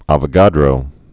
və-gädrō, ävō-), Amedeo 1776-1856.